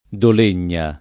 [ dol % n’n’a ]